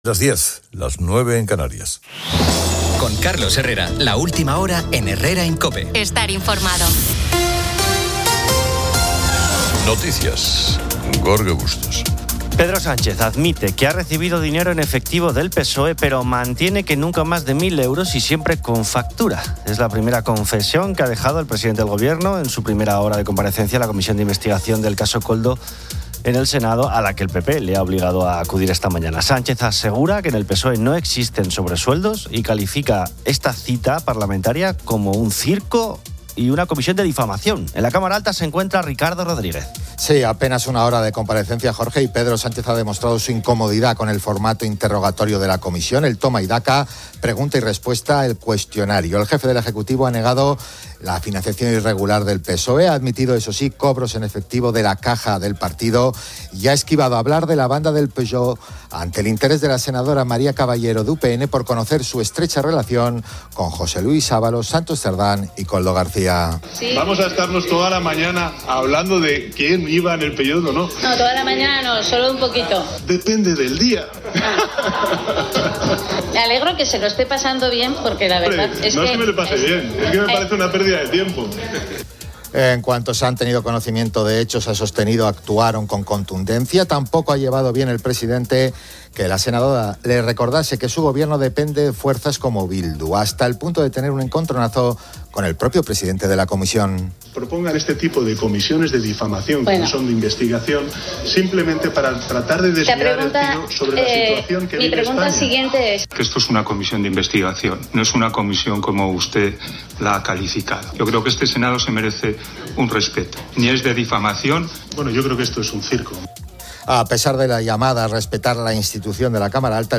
Las llamadas de los oyentes a COPE giran en torno a la forma de dormir: con ropa interior, pijama, o desnudo.